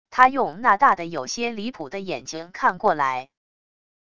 他用那大的有些离谱的眼睛看过来wav音频生成系统WAV Audio Player